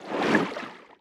Sfx_creature_seamonkey_swim_fast_05.ogg